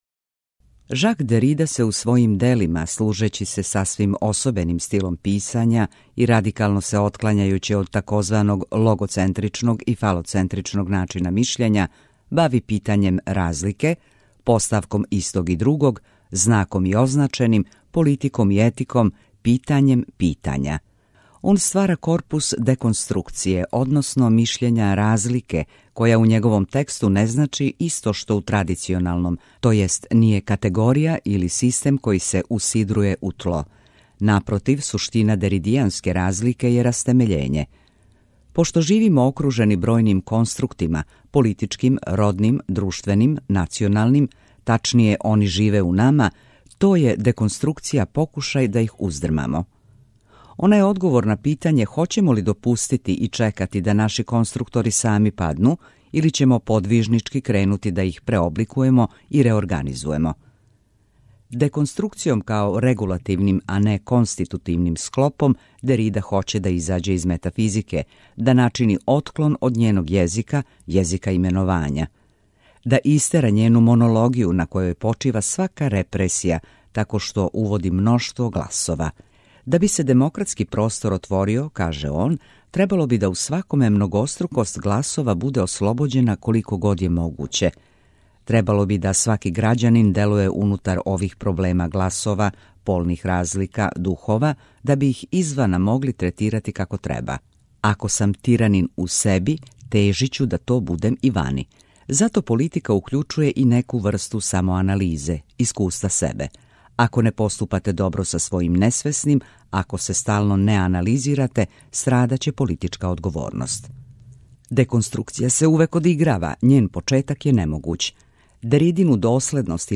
У емисијама РЕФЛЕКСИЈЕ читамо есеје или научне чланке домаћих и страних аутора.